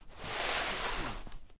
wall_slide.ogg